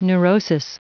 Prononciation du mot neurosis en anglais (fichier audio)
Prononciation du mot : neurosis